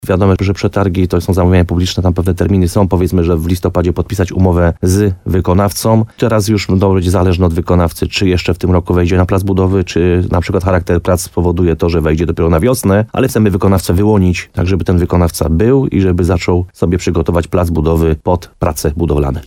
Jak powiedział w programie Słowo za Słowo w radiu RDN Nowy Sącz burmistrz Krynicy-Zdroju Piotr Ryba, tempo dalszych działań będzie zależało od firmy, który wygra przetarg.